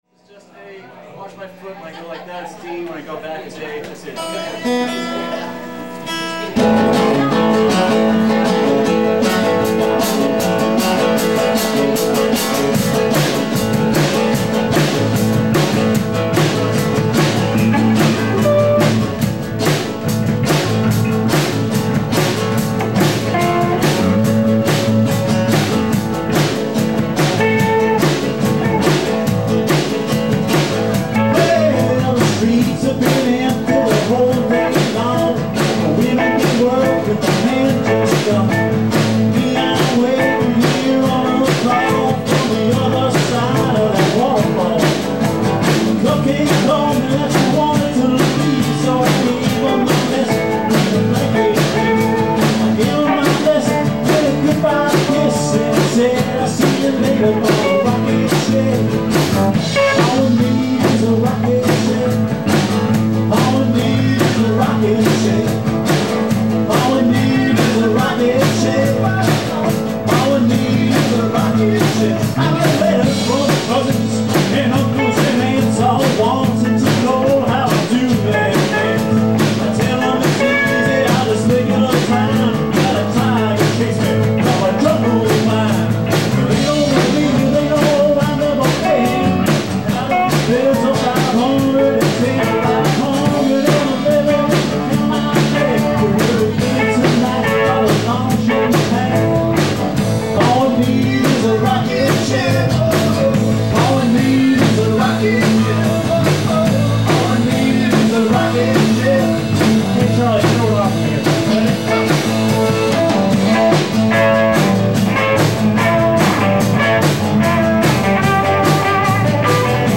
(9.3.05 Globe Coffee Lounge,
St. Petersburg, Fla.)